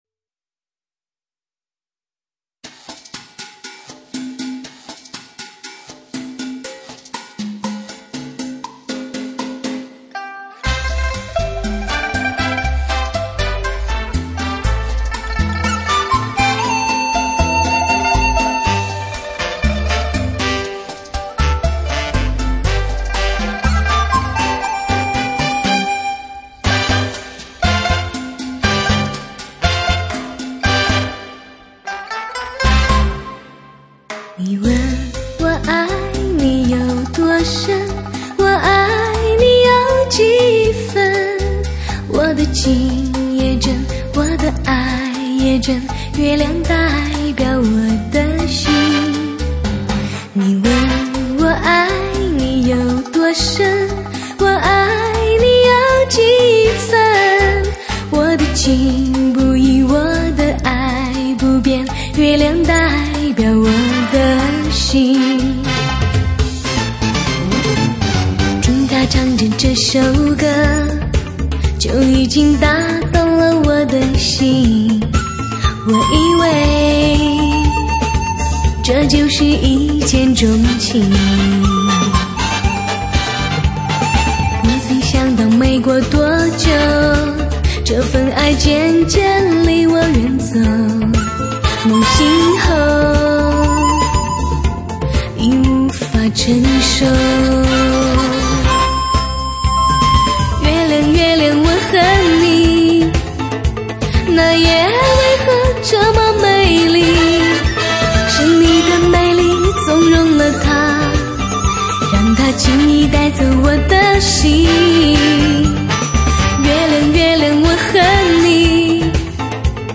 多无的和谐乐风  绝世珍稀的女声  带你进入美妙的音乐天堂